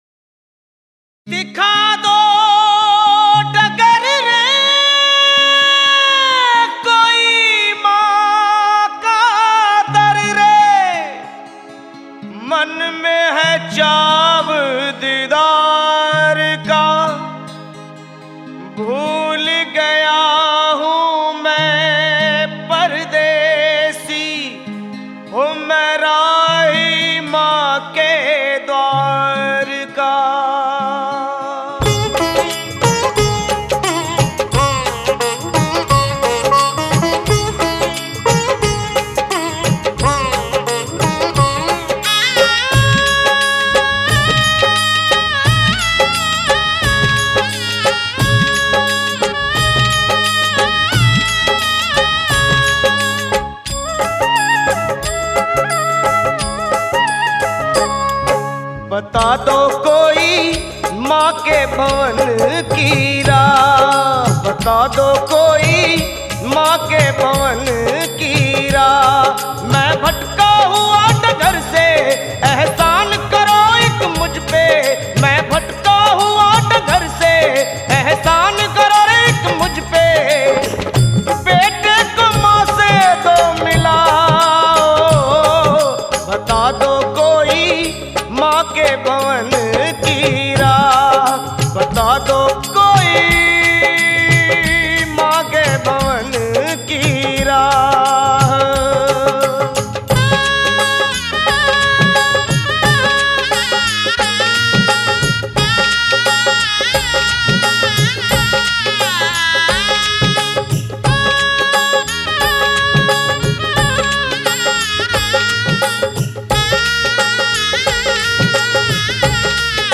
Bhakti Sangeet